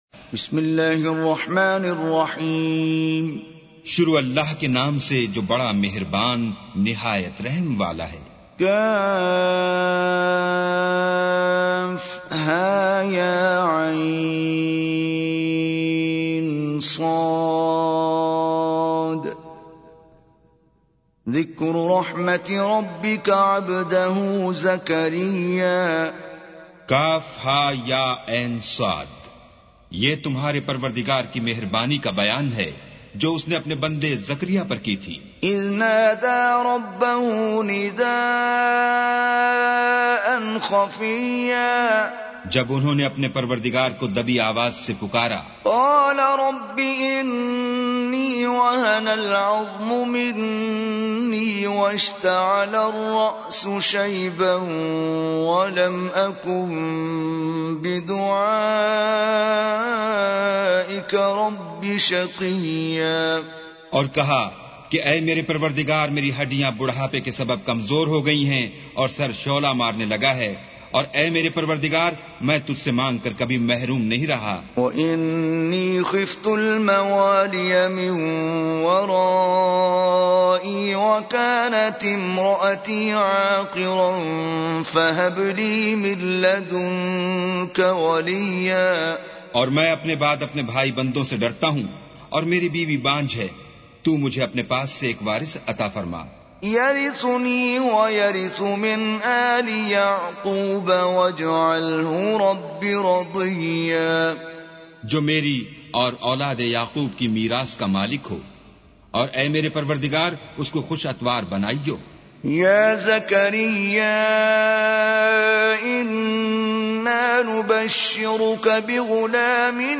Full Quran with Urdu Translation